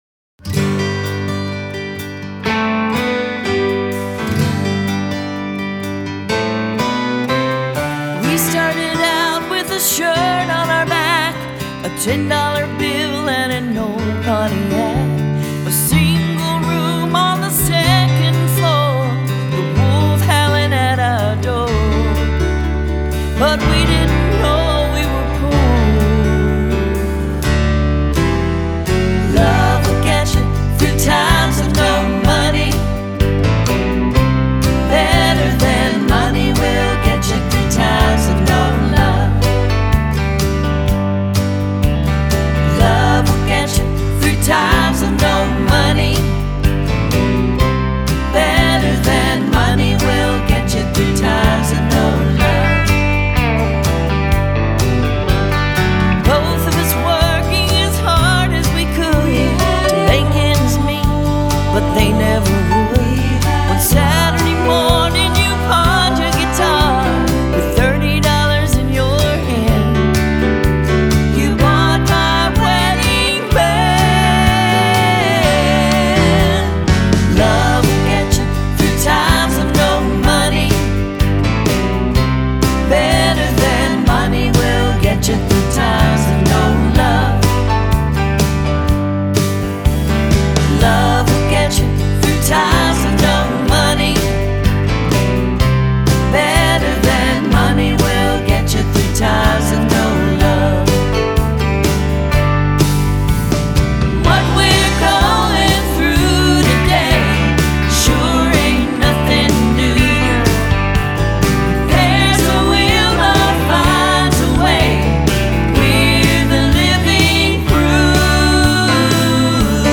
**GENRE: COUNTRY